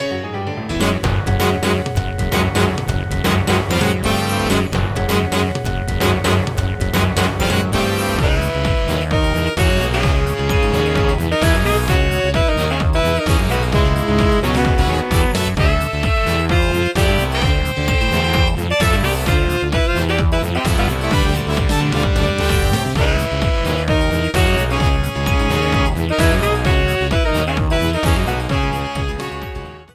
A boss battle theme
Ripped from game
clipped to 30 seconds and applied fade-out